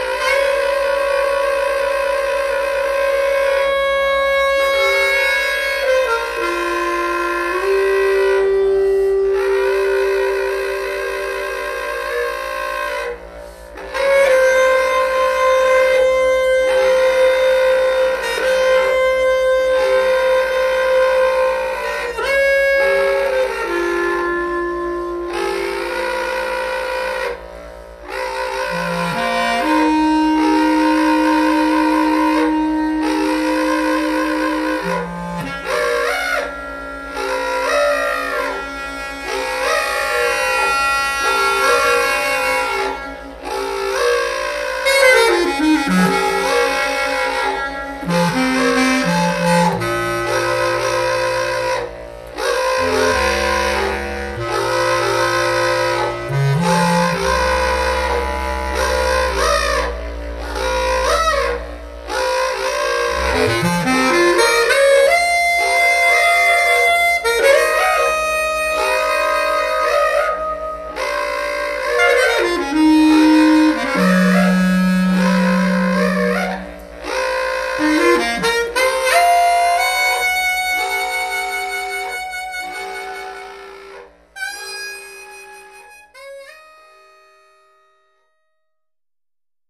Die erste Bassklarinette spielt im Rhythmus der Zirkularatmung ein etwas aufgeschrecktes, hysterisches Muster. Die zweite Klarinette improvisiert über dieses repititive Thema lange Sololinien, die klar tonal akzentuiert sind.
duo_bcl_128kb.mp3